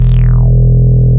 70.09 BASS.wav